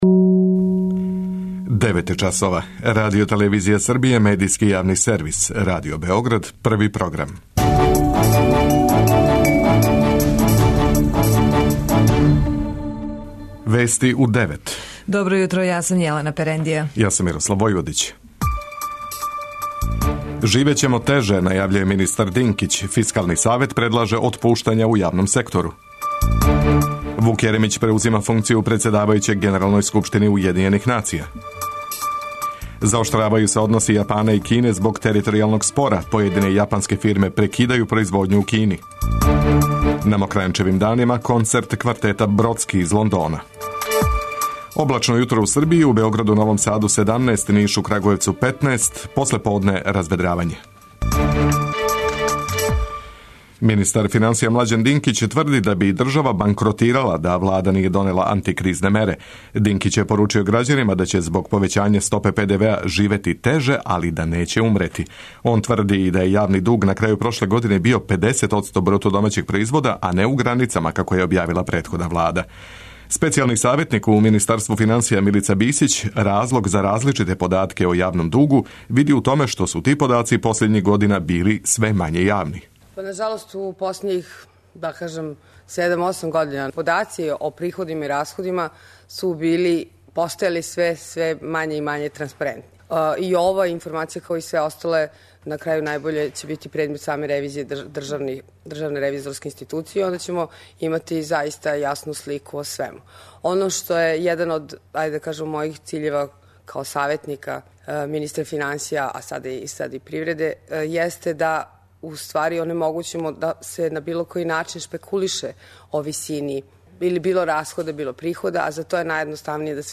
преузми : 9.83 MB Вести у 9 Autor: разни аутори Преглед најважнијиx информација из земље из света.